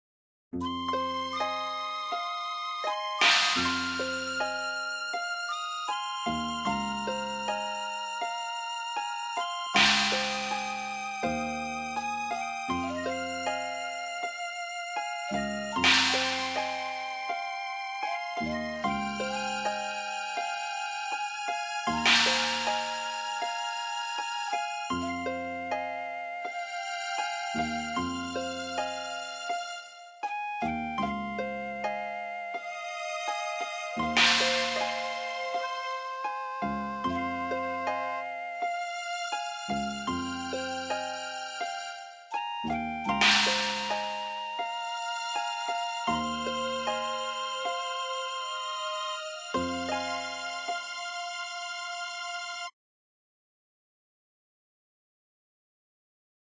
Another Asian Style Oriental Tune